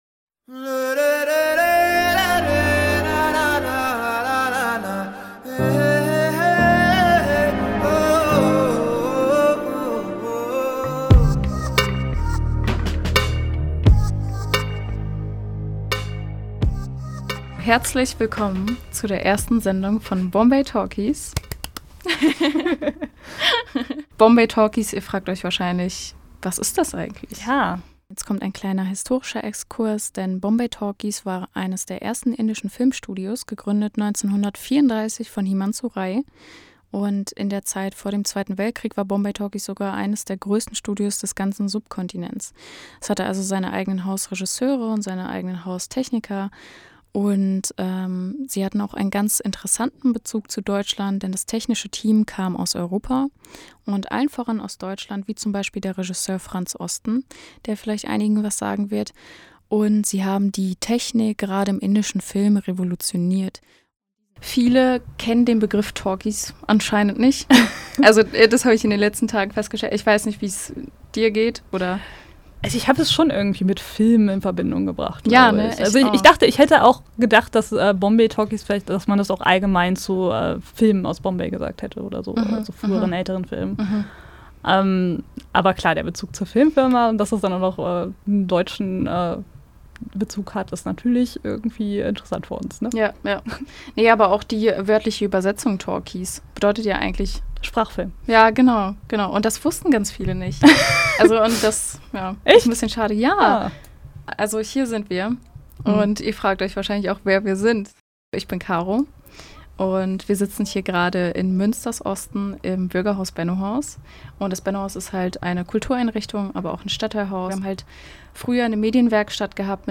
Hin und wieder sprechen wir auch mit Gästen oder Expert*innen.